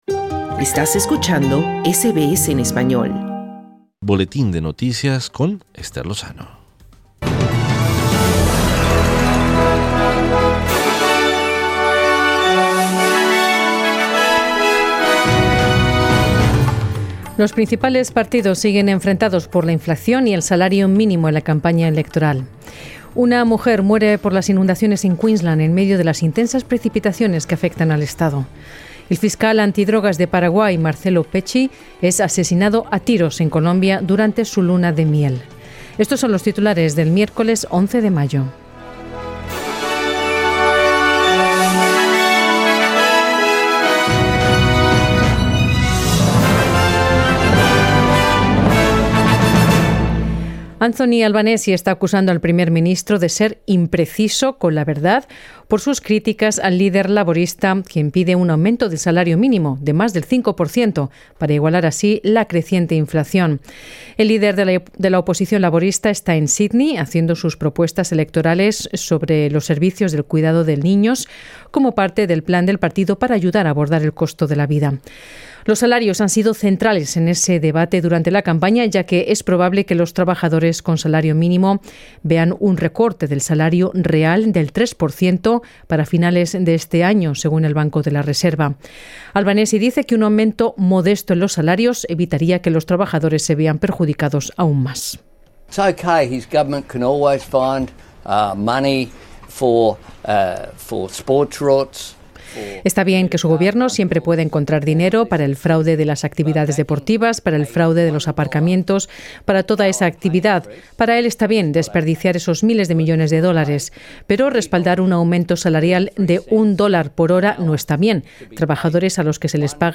Noticias SBS Spanish | 11 mayo 2022
Una mujer muere por las inundaciones en Queensland en medio de las nuevas precipitaciones que afectan al estado. Escucha esta y otras noticias importantes del día.